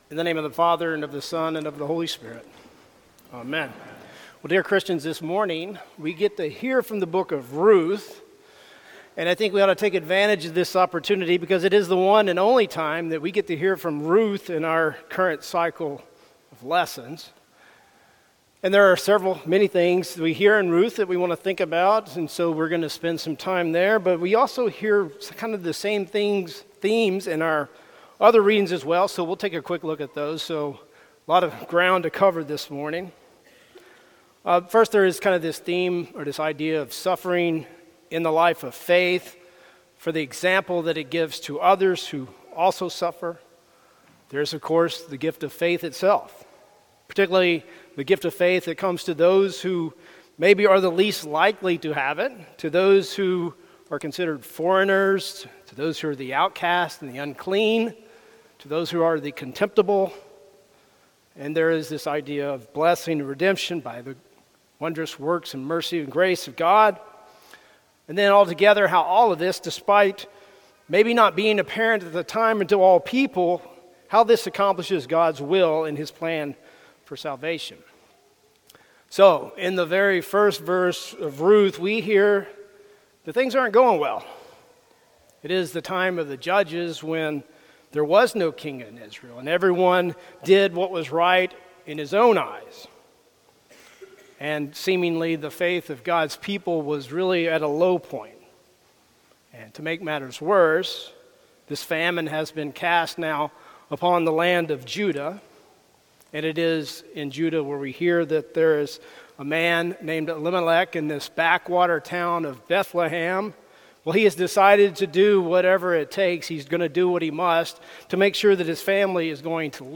Sermon for Eighteenth Sunday after Pentecost